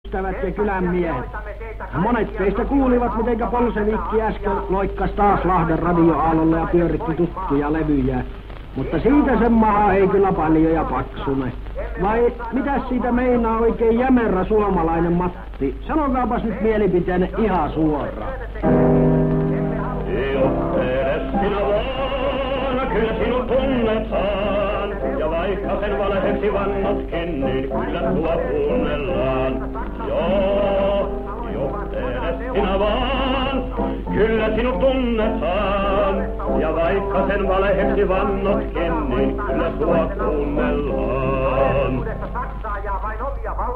Jahvetin kommenteista (YLE) Lahden radioaalloille tunkeutuneelle neuvostopropagandistille (Armas Äikiä?).